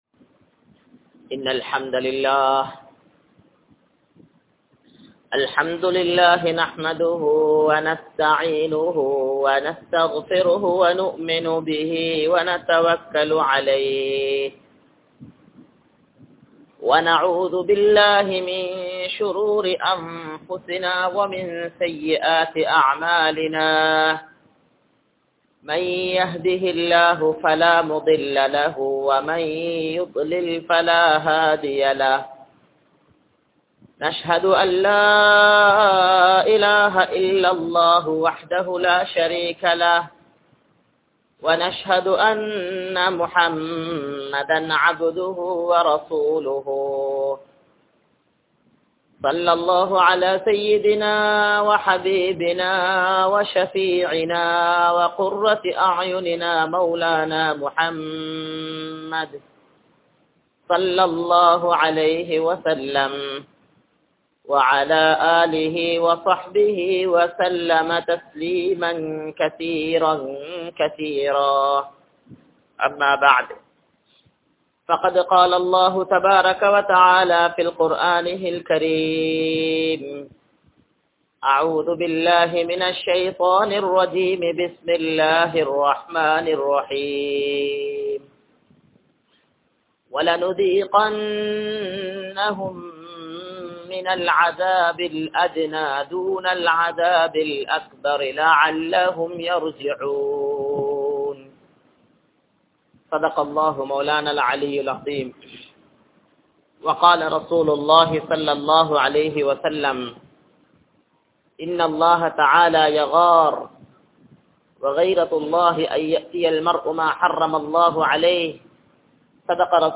Allah`vin Anpum Thandanaium (அல்லாஹ்வின் அன்பும் தண்டனையும்) | Audio Bayans | All Ceylon Muslim Youth Community | Addalaichenai